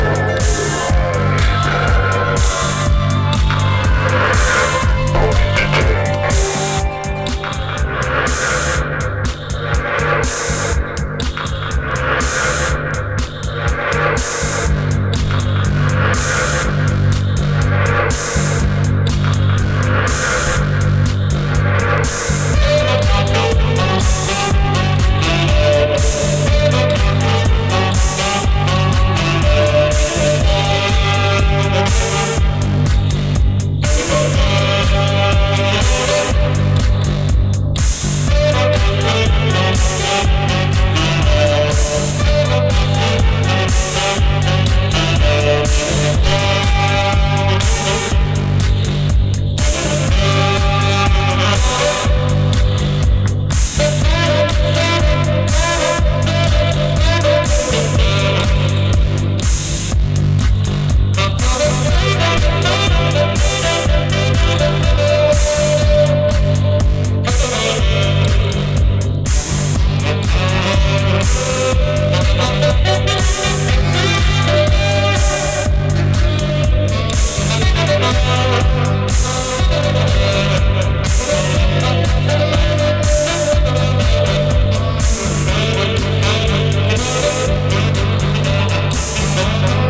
ファンキー、ダビー、ソウルフル…と各々のカラーが映えた抜群の仕上がりです。